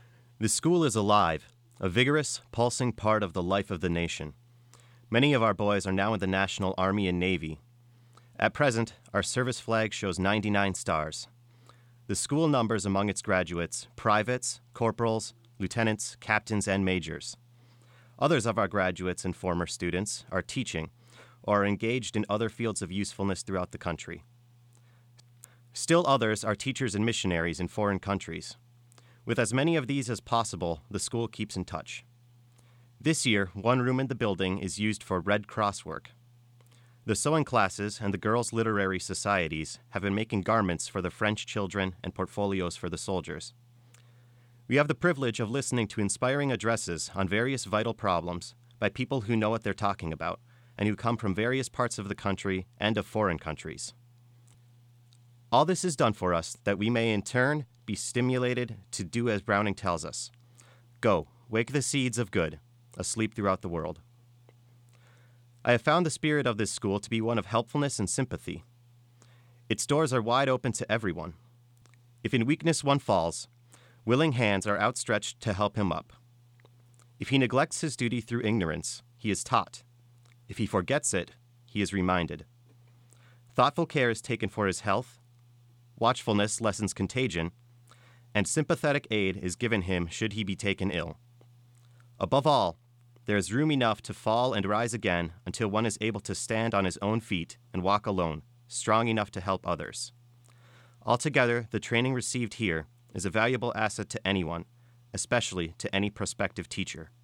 Recorded partial reading of an article entitled "A Tribute to the St .Cloud Normal School"